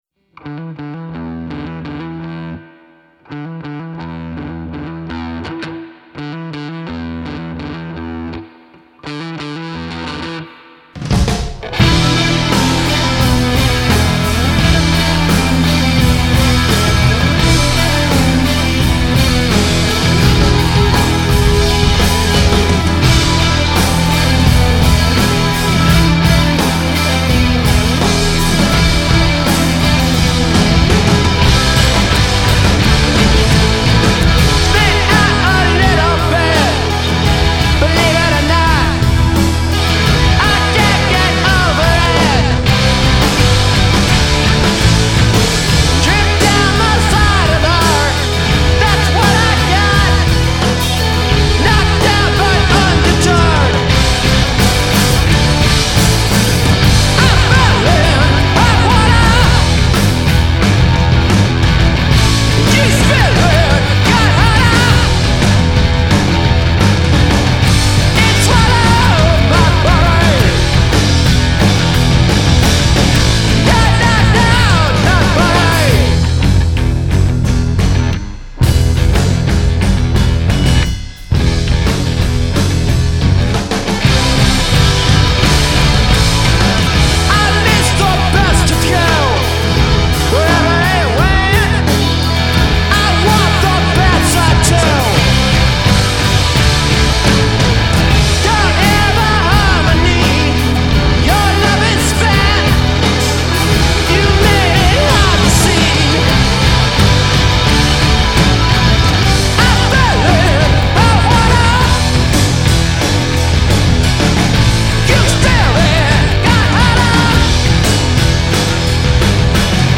is a race through madness, in the best of ways.